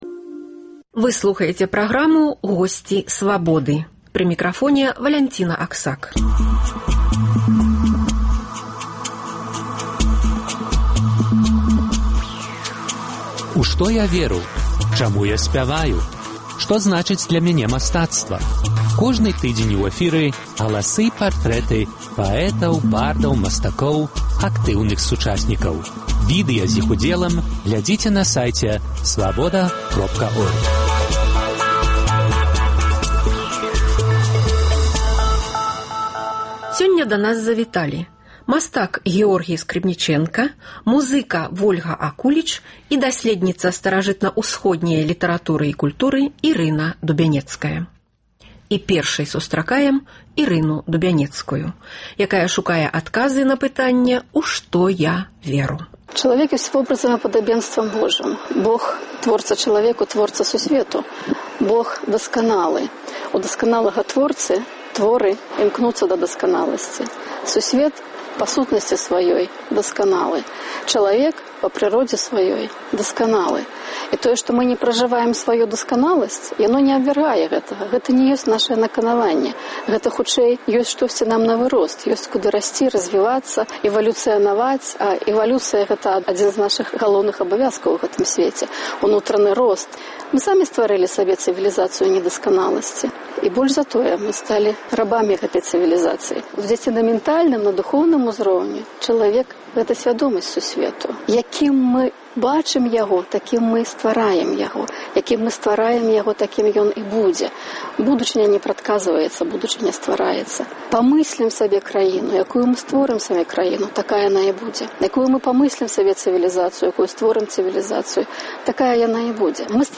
Галасы і партрэты паэтаў, бардаў, мастакоў, актыўных сучасьнікаў.